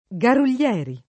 [ g arul’l’ $ ri ]